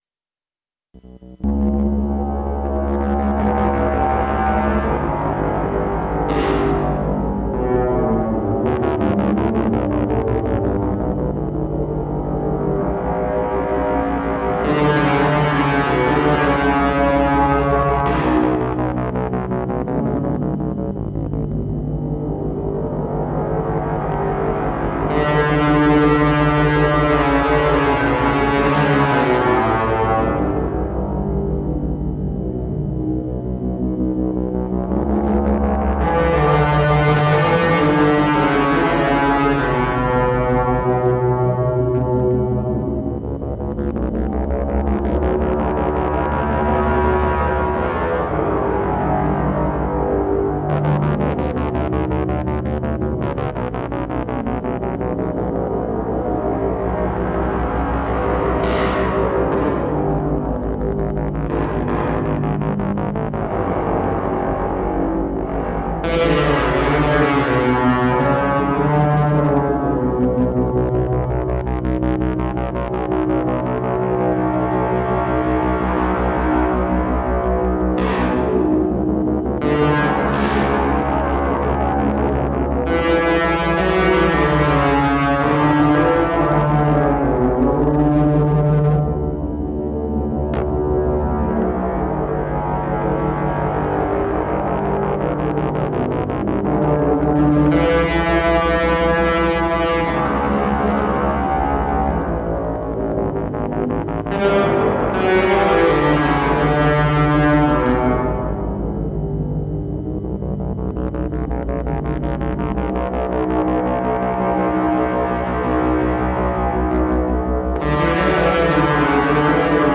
Kopfhörer oder Stereo-Lautsprecher empfohlen.
Behringer DR600
experimentell, schmutzig, depressiv
nur echte Instrumente und Effektgeräte